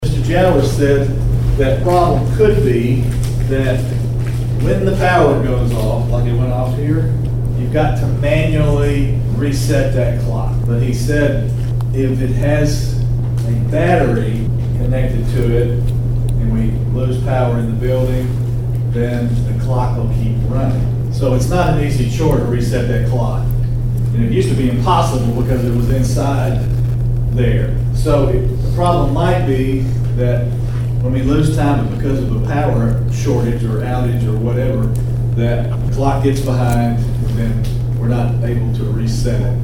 Martin Mayor David Belote said it would cost $1,800 to remove the clock and $800 to fix the time.